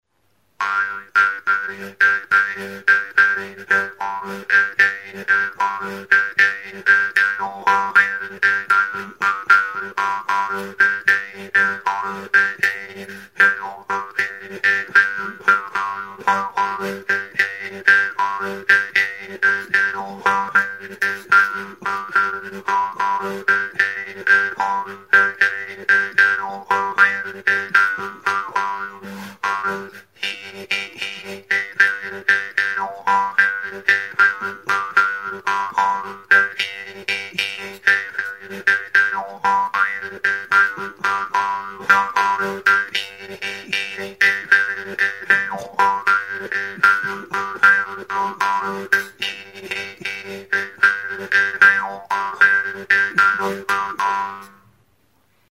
Idiophones -> Plucked / flexible -> Without sound board
Recorded with this music instrument.
TRONPA; MOSUGITARRA; MOSUMUSIKA
Altzairuzko mihi luzea du uztaiaren erdi-erdian, hatzarekin astintzerakoan libre bibratzen duena.